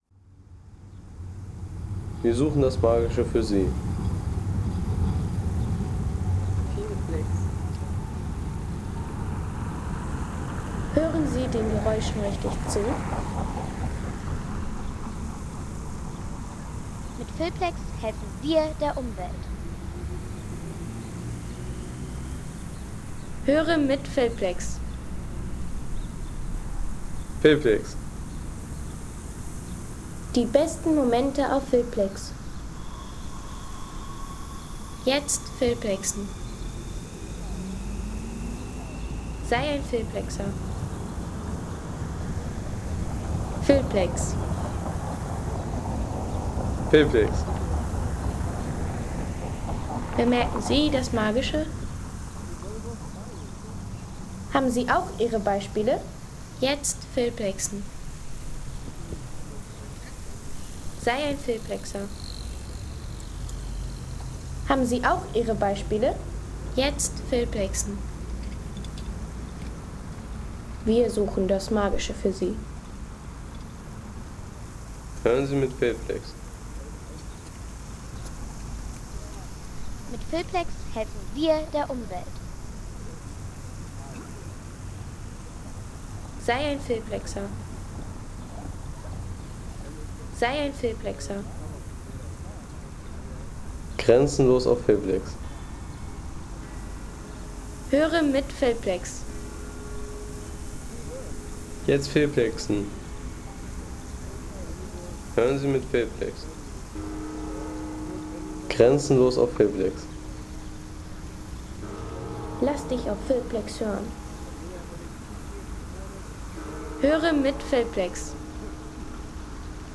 Erholungsgenuss für alle, wie z.B. diese Aufnahme: Soundkulisse am Augustusplatz Leipzig
Soundkulisse am Augustusplatz Leipzig